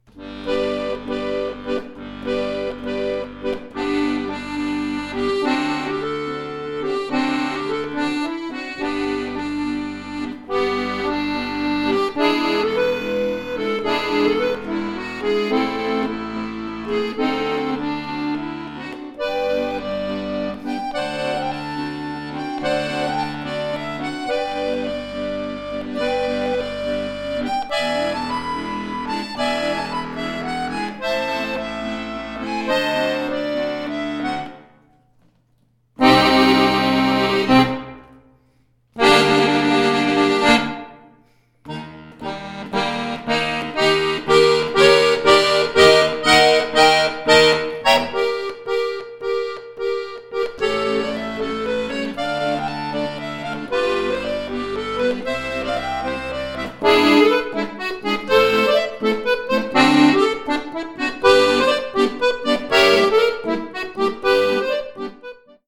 Arrangement für Akkordeon solo
Klassisch